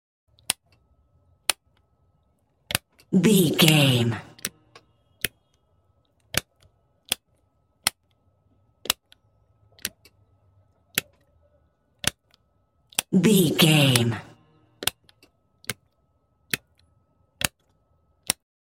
Ambulance on off buttons clicks
Sound Effects